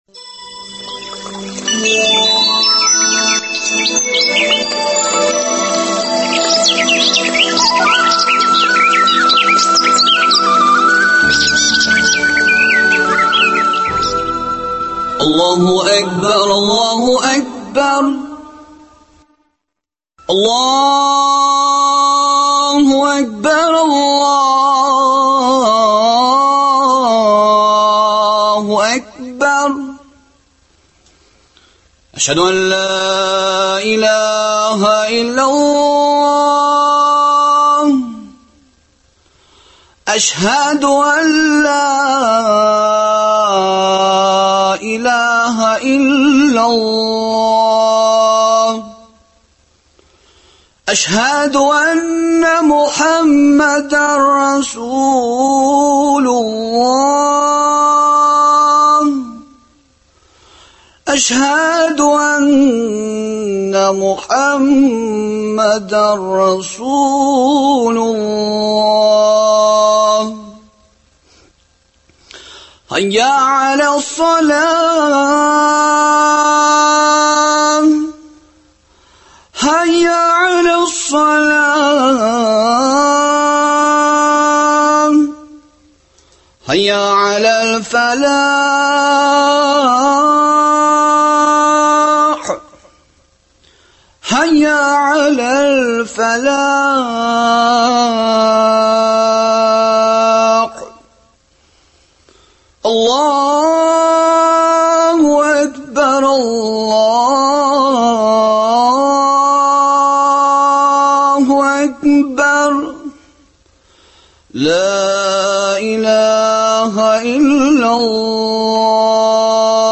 Шушы факторлар, бу бәйрәмнәр турында студиядә